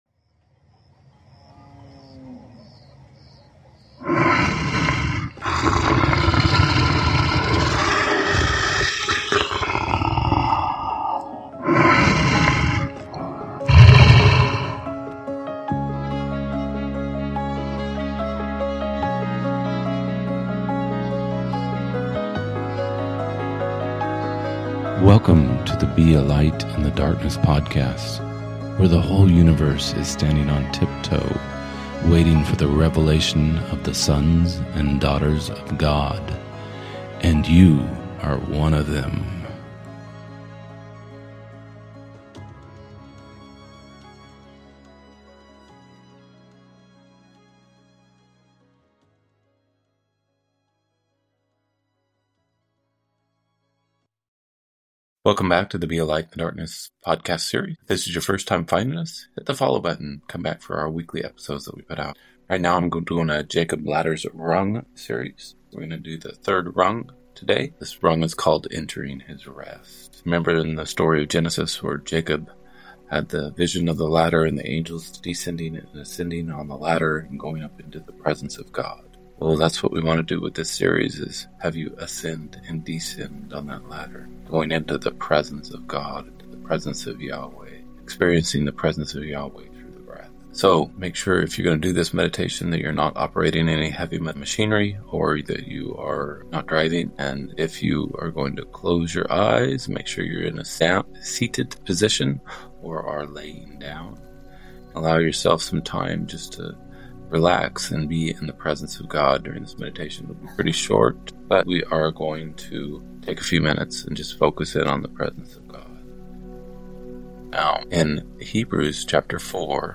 spirituality, meditation, breath, presence of God, Jacob's Ladder, faith, confidence, Yahweh, Christian meditation, rest
- Try this: inhale “Yah,” exhale “Way”—it’s a simple practice that can feel like it’s just for you, calming and centering.